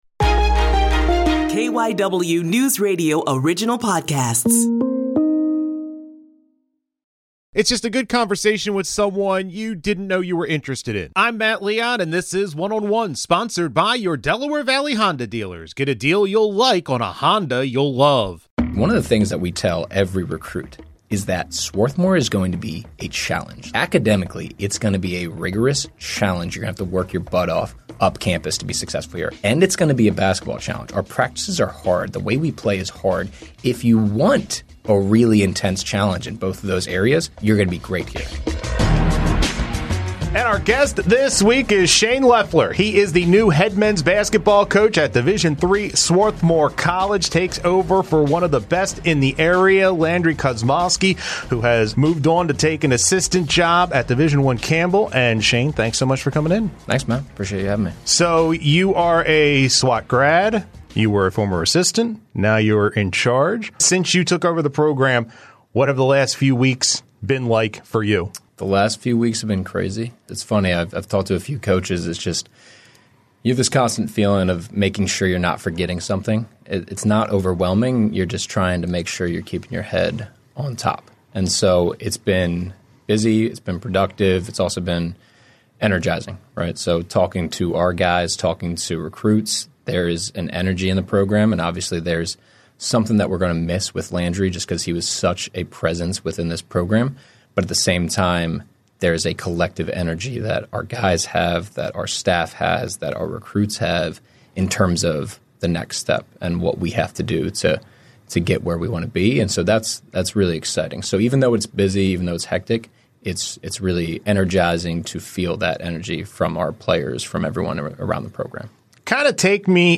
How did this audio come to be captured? in studio to talk about his career